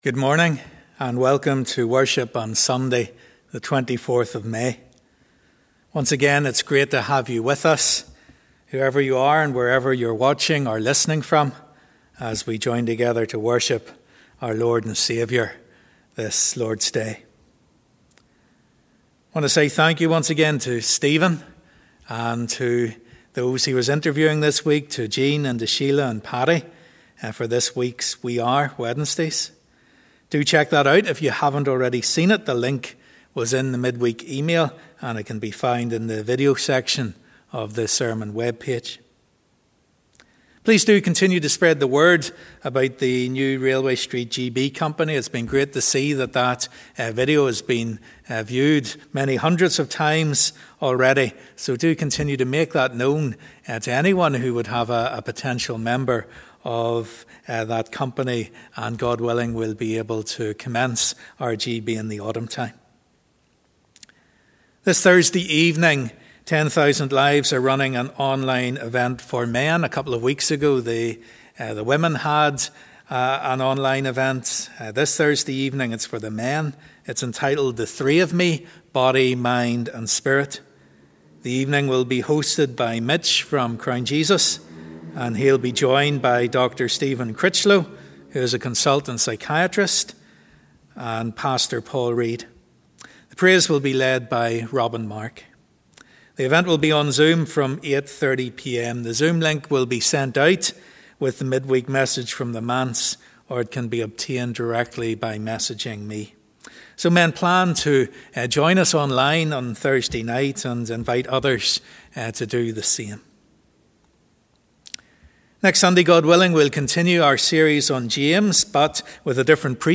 As we come to worship we join in the words of 'Come People of the Risen King'.
As we conclude let’s use the song 'May the Mind of Christ My Saviour'.